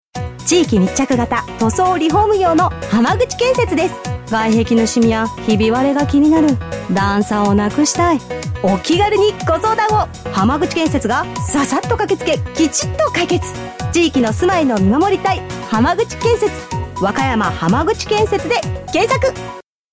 １１月より＃FM８７７でCM放送が毎日流れています！